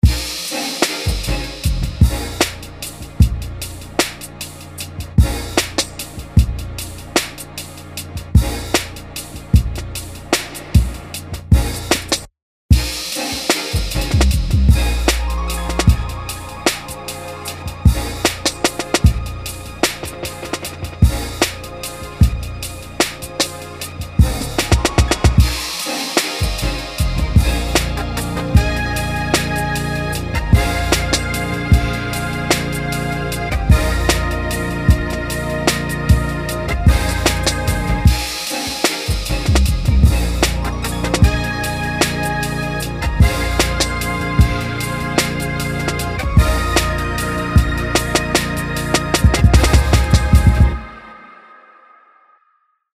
Erlesene HipHop-Melodik